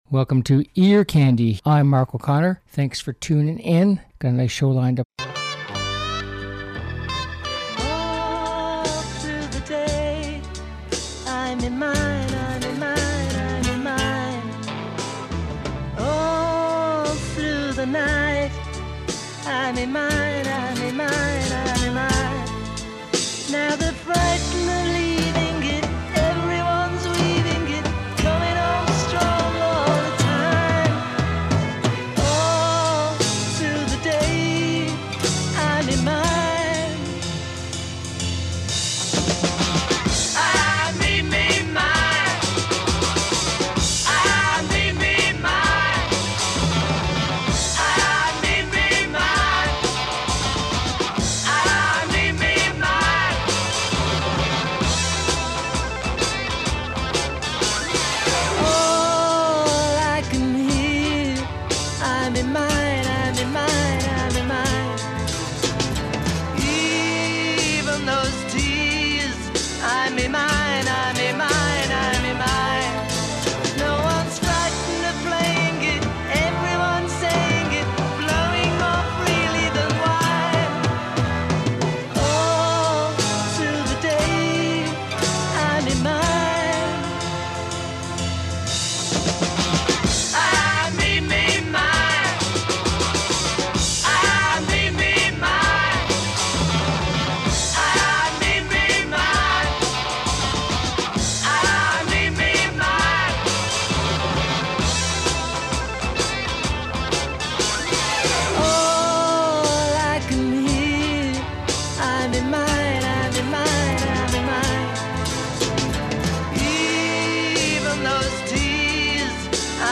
Uptempo Rock and Pop Songs